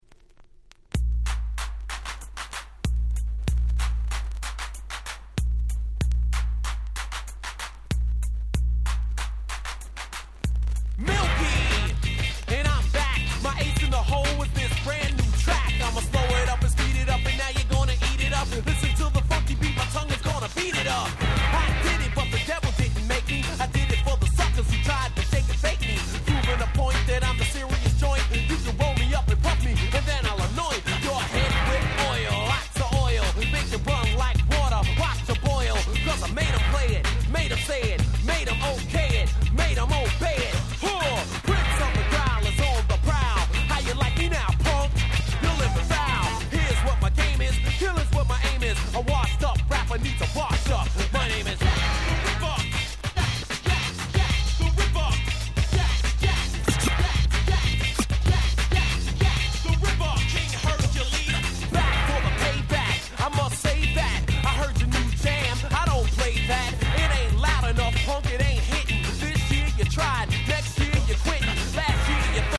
90's 80's Boom Bap ブーンバップ Old School オールドスクール